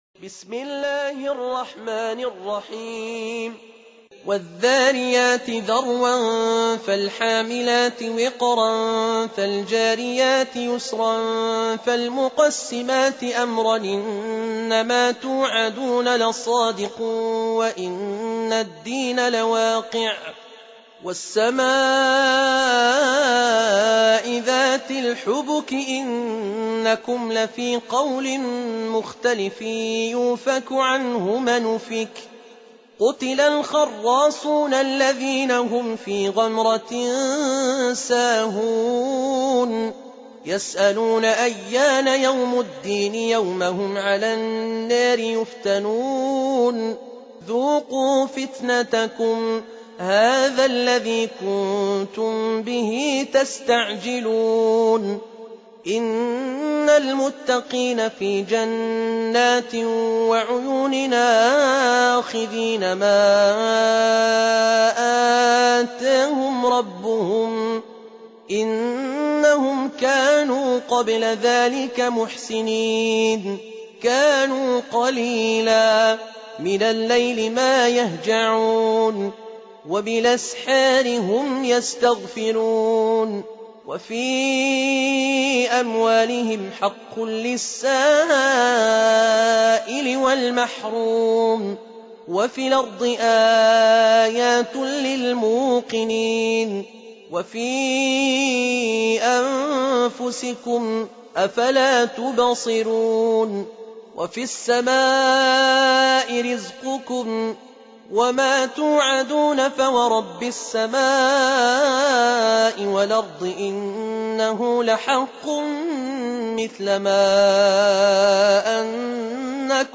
51. Surah Az-Z�riy�t سورة الذاريات Audio Quran Tarteel Recitation
Surah Repeating تكرار السورة Download Surah حمّل السورة Reciting Murattalah Audio for 51.